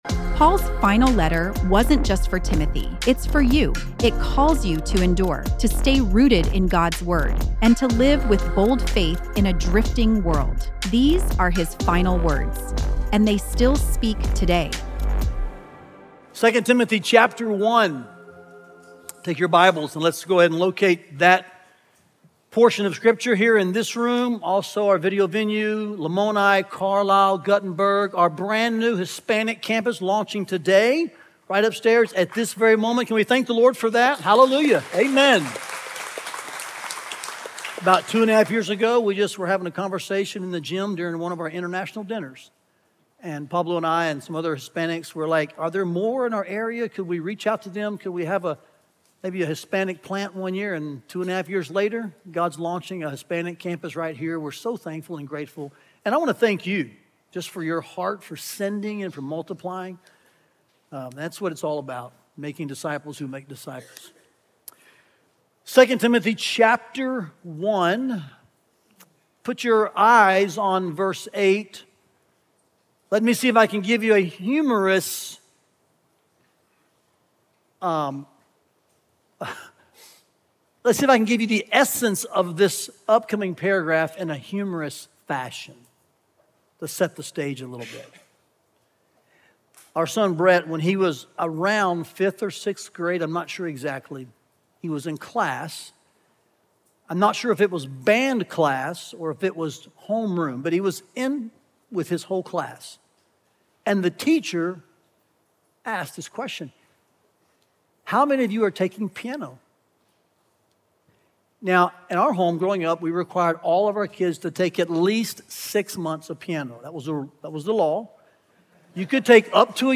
The Holy Spirit shapes me through the gospel to suffer for the gospel. Listen to the latest sermon from our 2 Timothy series, Final Words, and learn more about the series here.